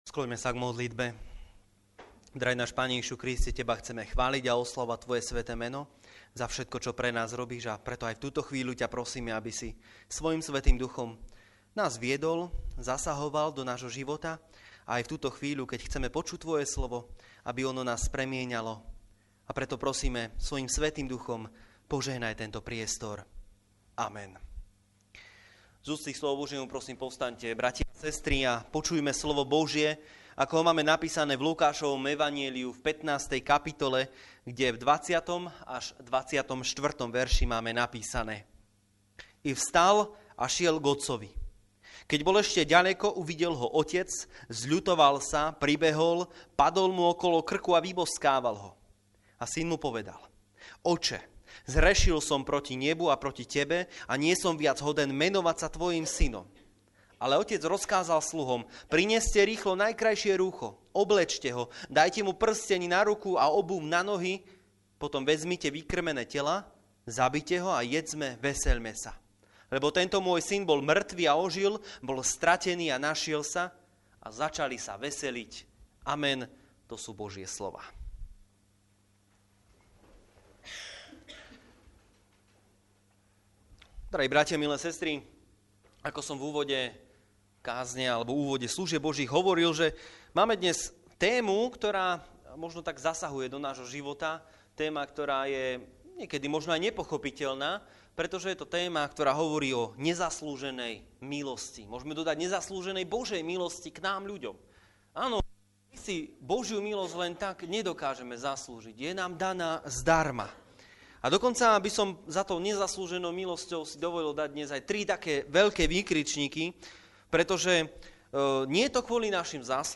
Nedeľa Deviatnik: Aj my sa podobáme stratenému synovi...
24 Service Type: Služby Božie Nedeľa Deviatnik « Pán Ježiš má moc nad všetkým!